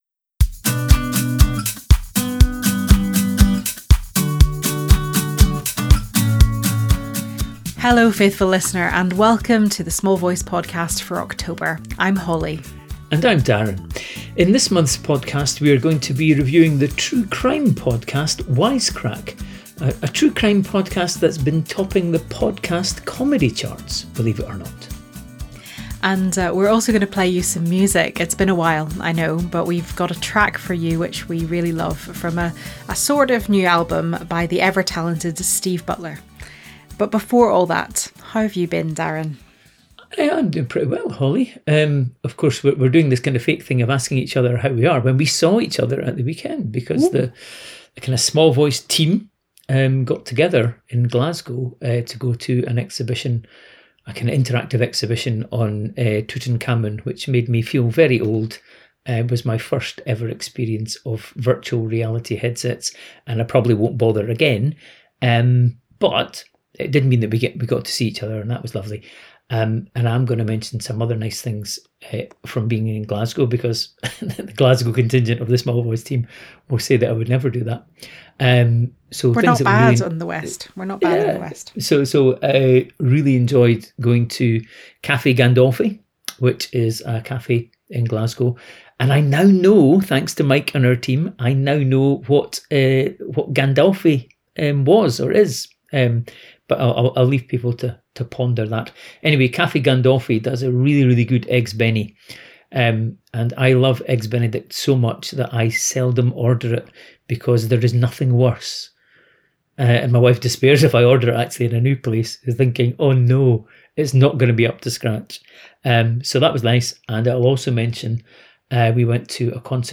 We’re also delighted to play a bit of music!